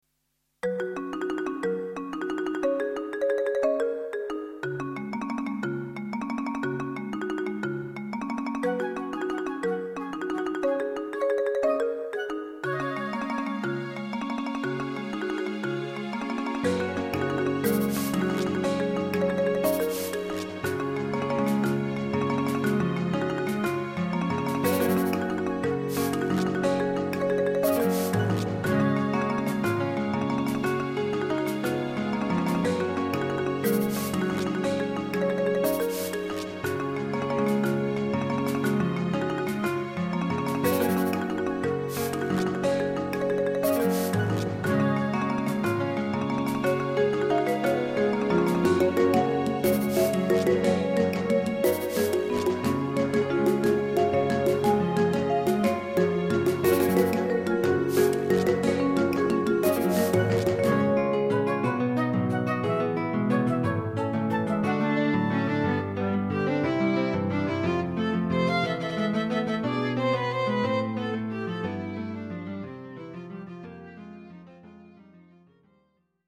klassiek
Jungle-achtige sfeer, achtergrondmuziek